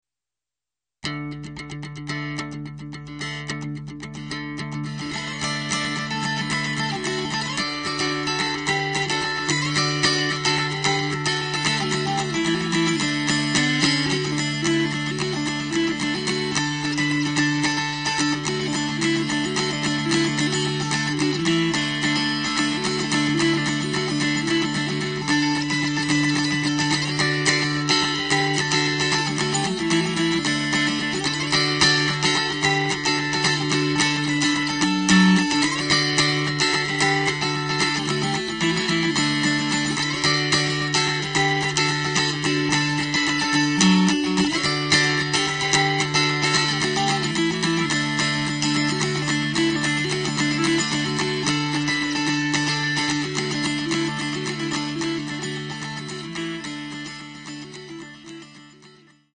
arrangements for dancing.